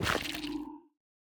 Minecraft Version Minecraft Version 1.21.5 Latest Release | Latest Snapshot 1.21.5 / assets / minecraft / sounds / block / sculk / place3.ogg Compare With Compare With Latest Release | Latest Snapshot